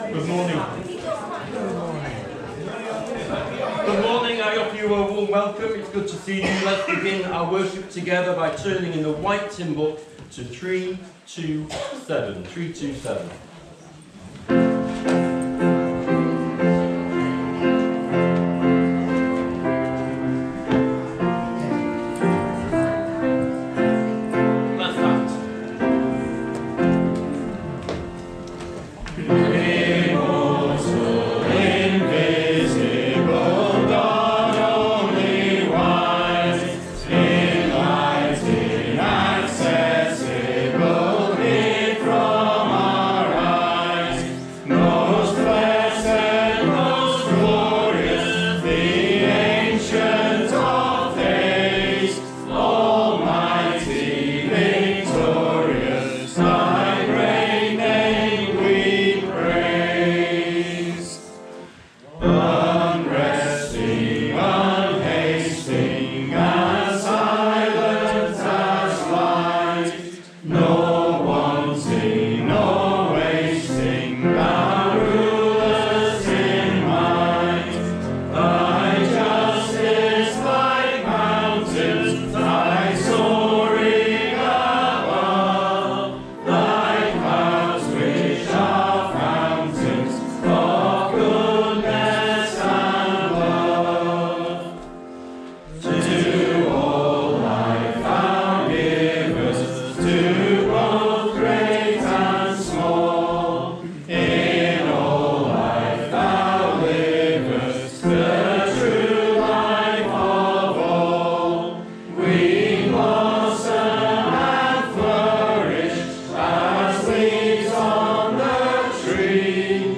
Below is audio of the full service.